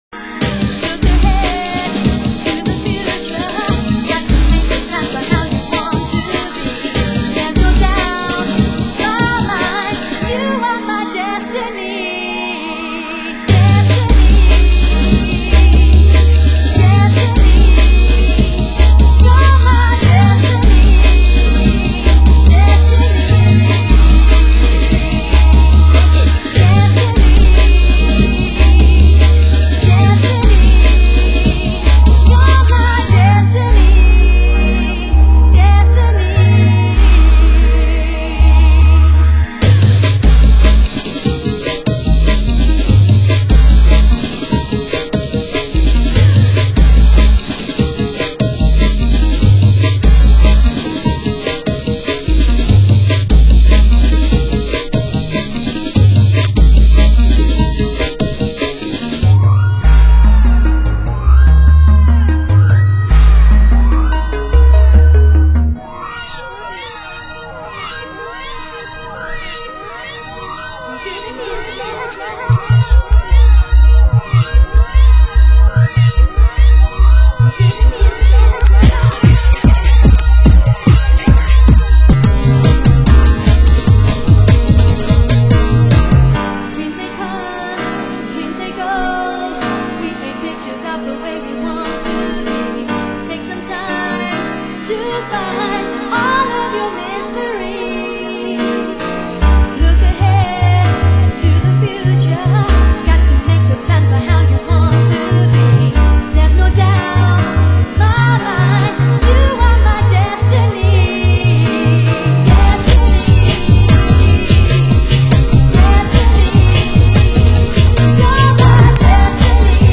Genre: Break Beat